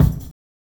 KICK GRITTY 2.wav